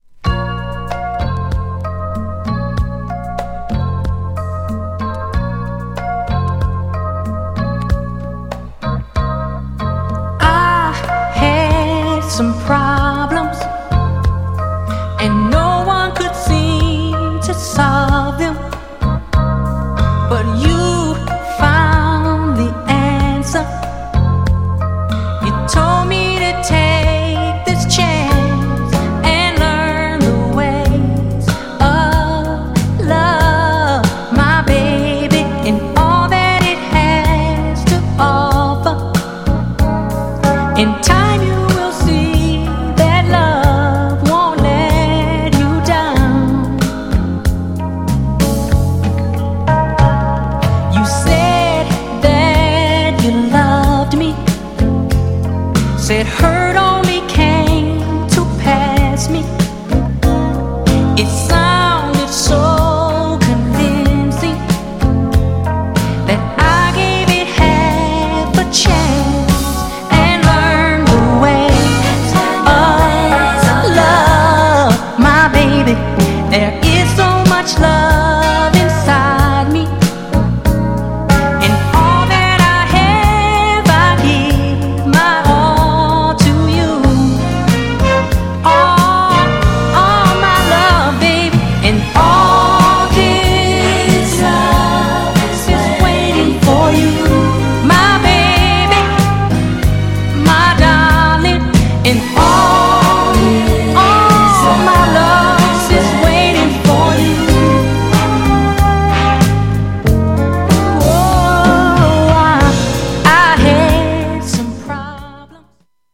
3rdアルバムからカットのメロウなミディアム/スロー!!
GENRE Dance Classic
BPM 86〜90BPM
# ドラマティック
# 切ない感じ